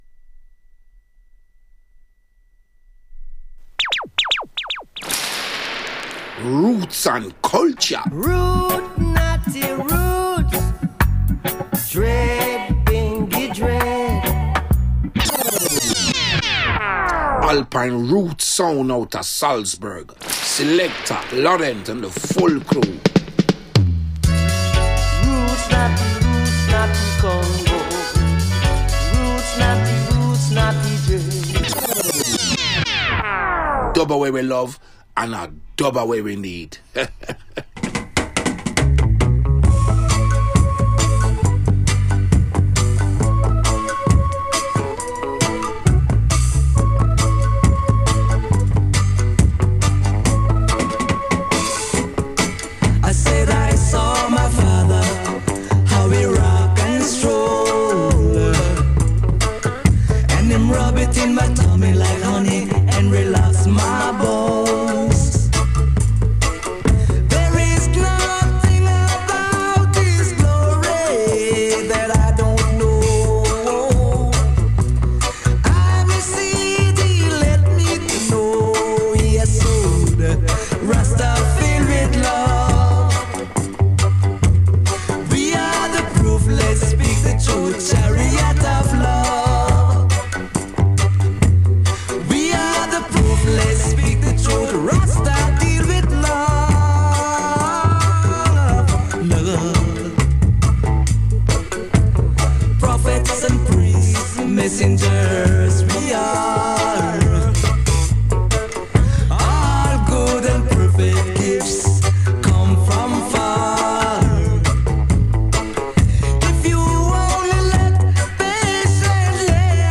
Women in Reggae Special Live Radioshow